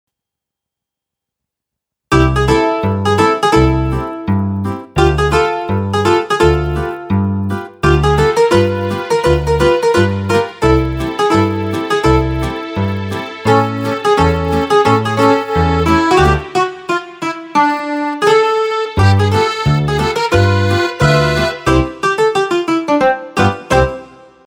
Patch Demos
8. Zither
Zither.mp3